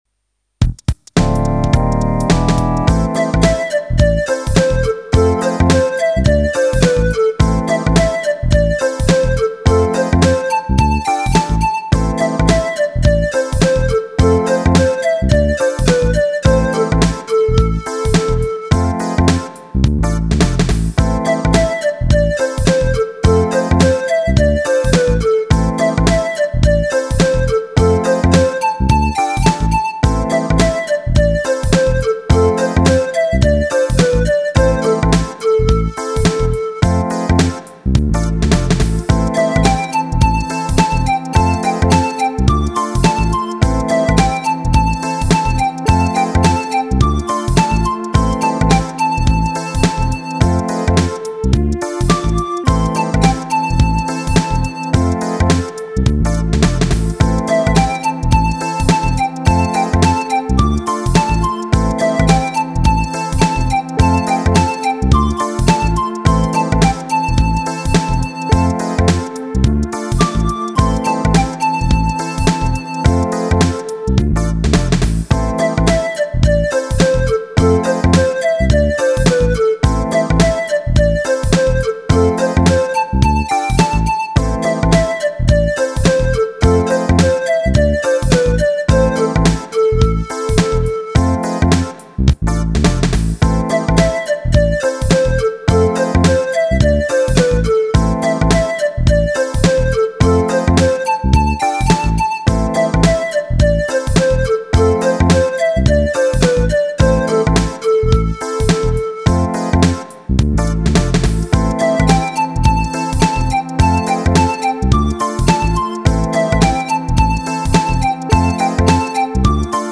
Ik voeg steeds nieuwe ritmedemo's bovenaan toe, scroll dus naar beneden om alles te zien.
029 16 Beat Funk